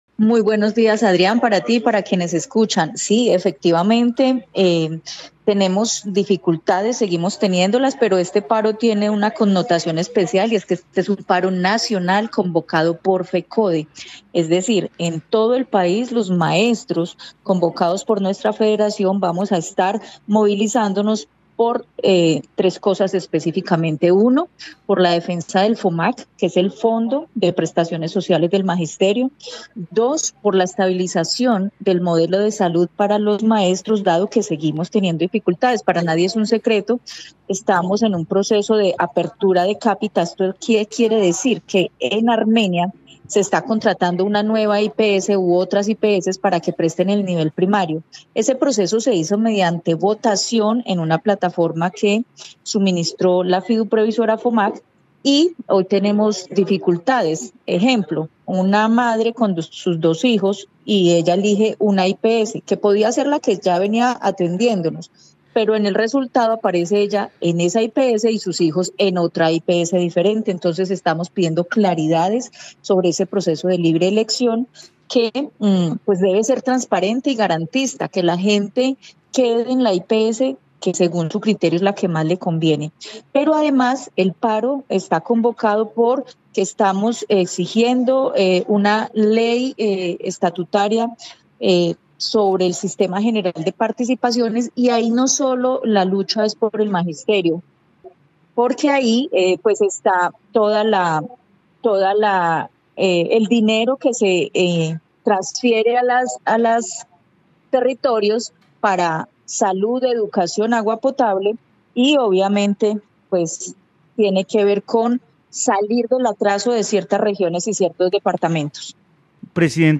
En Caracol Radio Armenia hablamos con